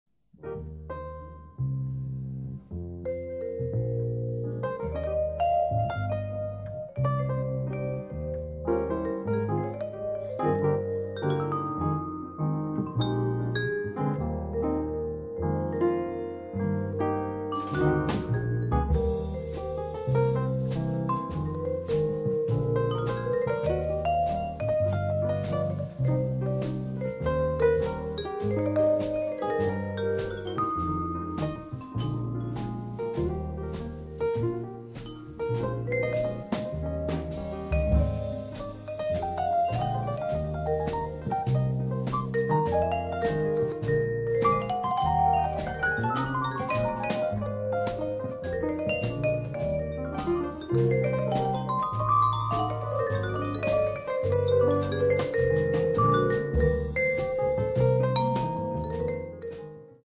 vibrafono
pianoforte
contrabbasso
batteria
Ambienti blue, romantici ed avvolgenti si ascoltano